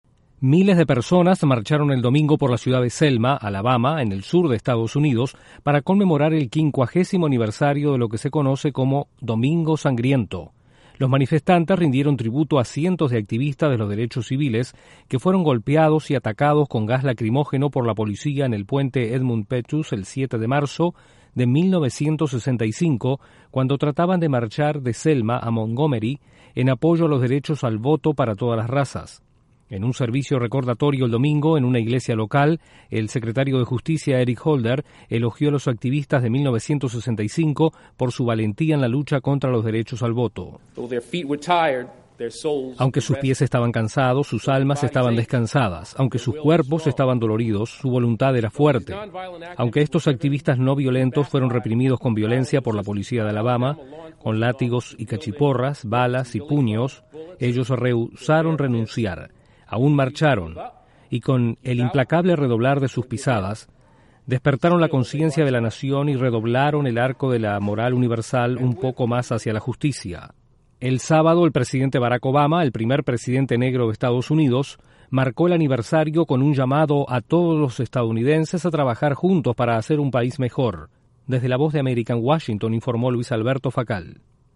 Estados Unidos recordó a activistas de los derechos civiles 50 años después de la marcha en Alabama. Desde la Voz de América en Washington informa